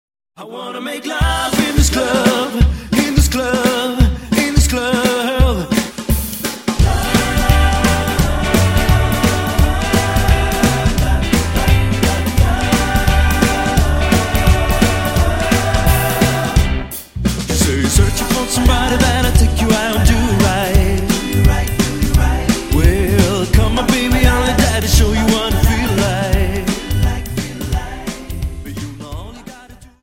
Dance: Jive